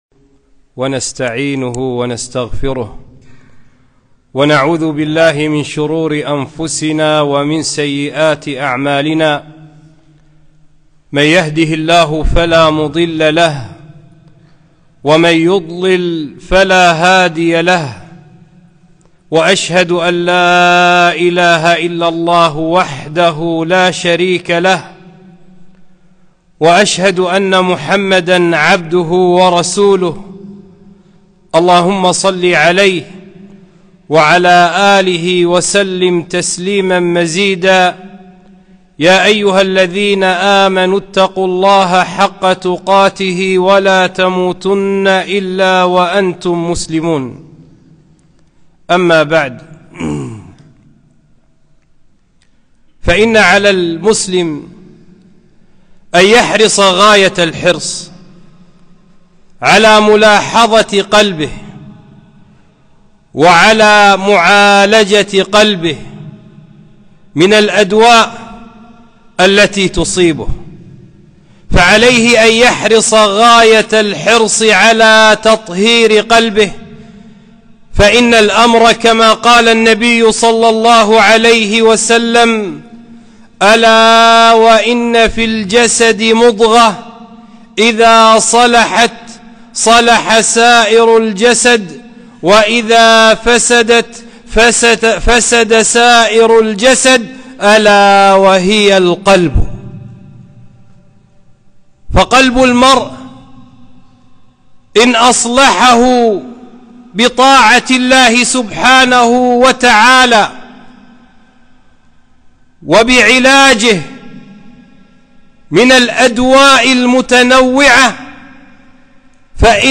خطبة - (الكبر بطر الحق وغمط الناس)